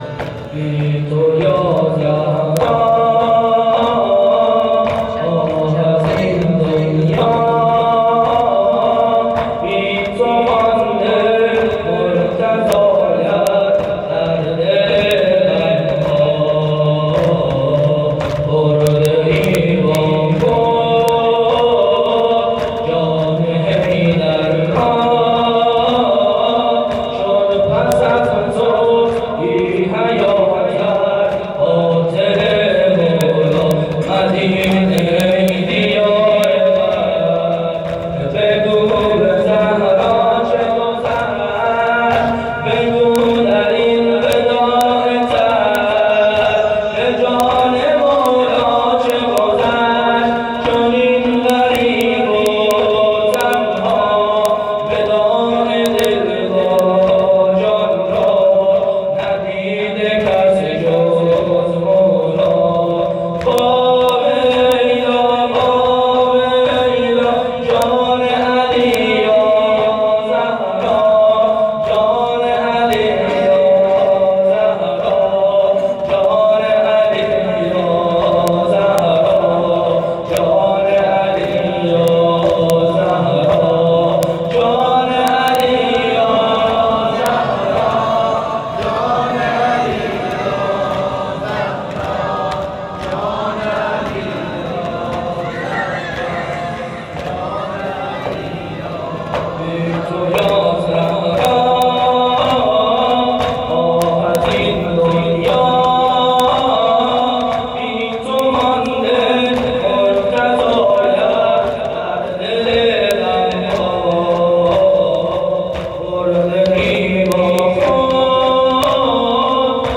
مداحی زابلی
2-زمینه-بی-تو-یازهرا.mp3